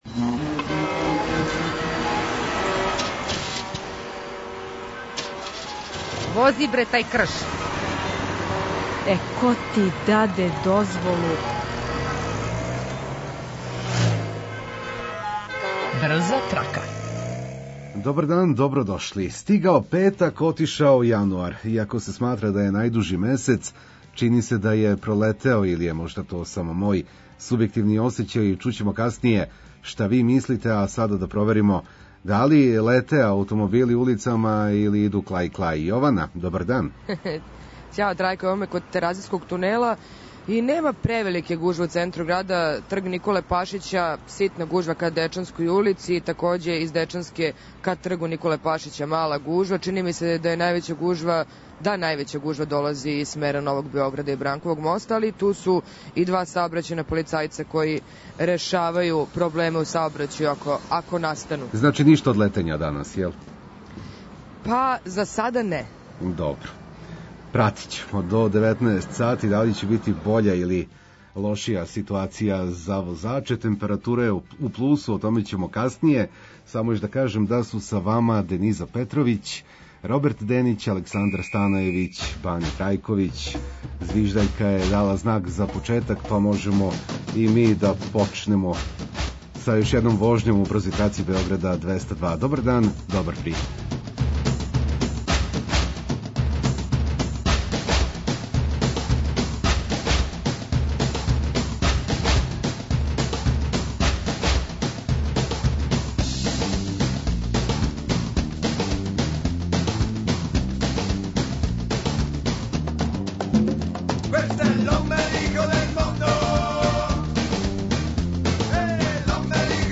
Поподневно време за вожњу или популарно названо „drive time”, идеална је прилика да причамо о саобраћају, да помажемо једни другима у смислу обавештавања о гужвама, препоручујемо алтернативне правце и уз све то слушамо сјајну музику.